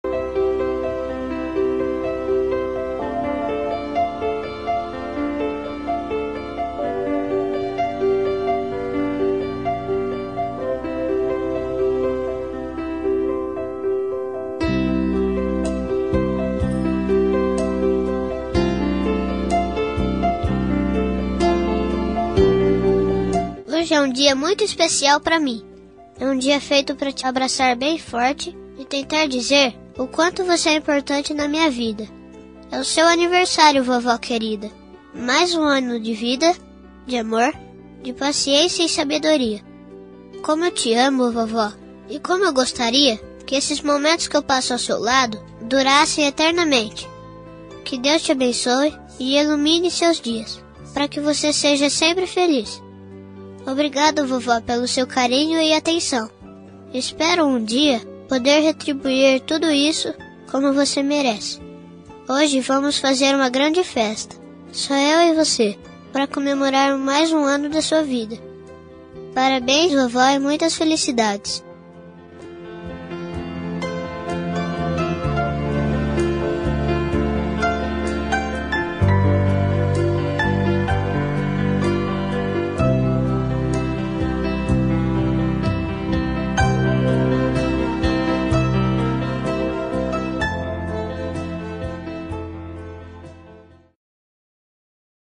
Aniversário Voz Infantil – Avó – Voz Masculina – Cód: 258102